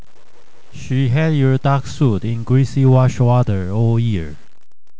使用我講的
好的測試語句，下圖是波形及經由 Forced Alignment 的結果，基本上的切音位置都是正確的：